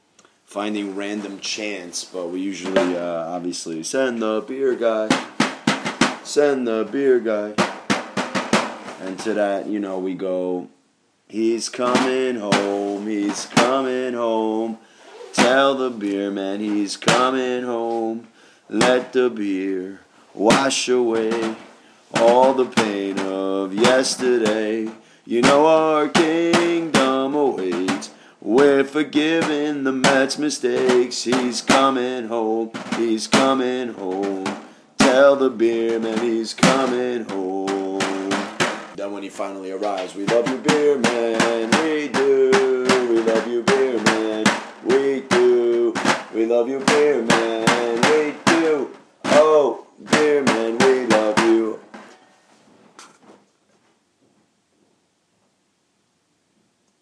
old beer man chants